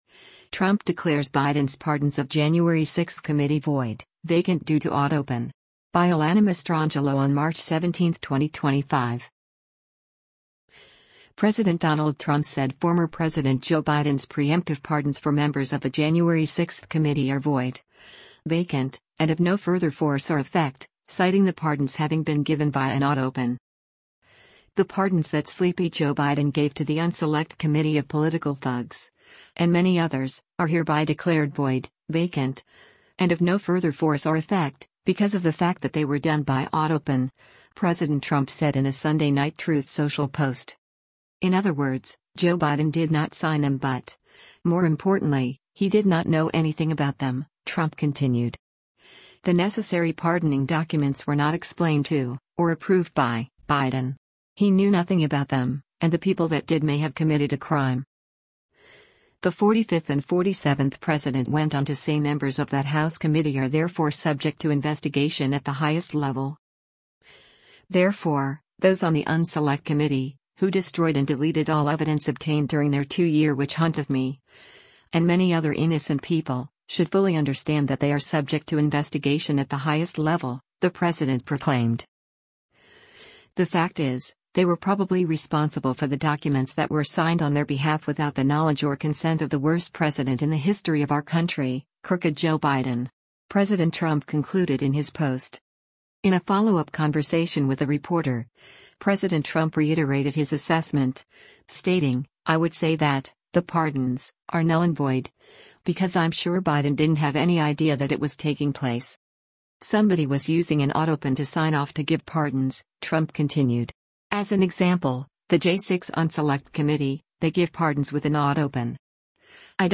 In a follow-up conversation with a reporter, President Trump reiterated his assessment, stating, “I would say that [the pardons] are null and void, because I’m sure Biden didn’t have any idea that it was taking place.”